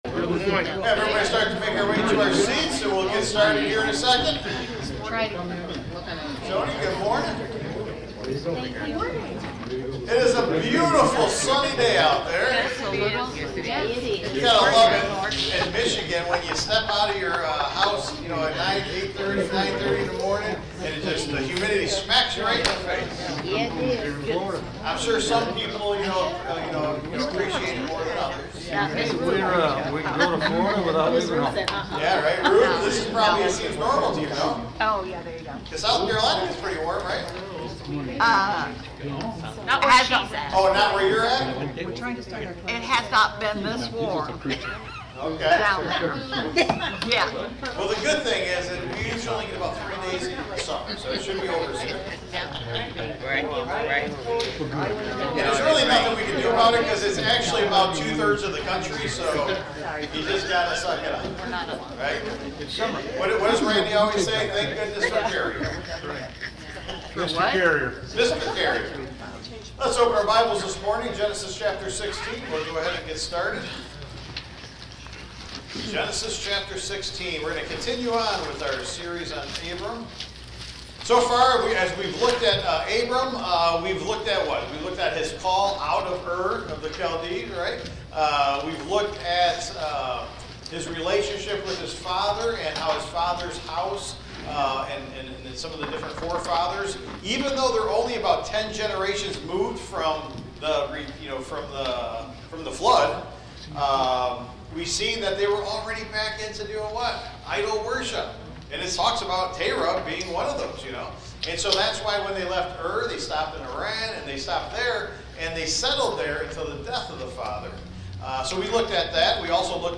Taught live Sunday, June 22, 2025
BIbleStudy